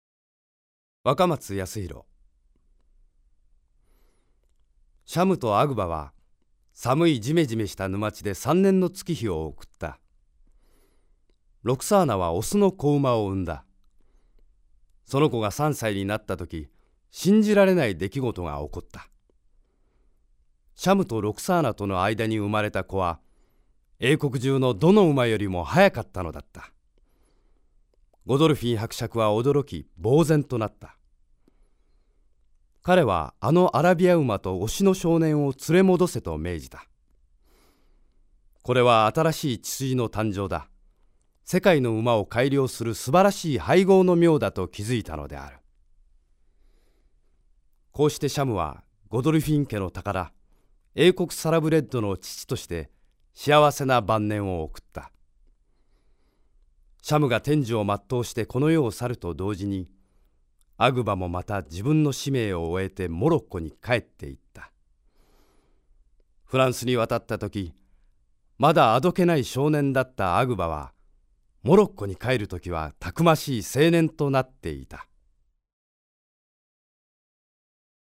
方言：大阪弁
ボイスサンプル